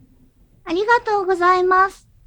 ダウンロード 女性_「ありがとうございます」
女性挨拶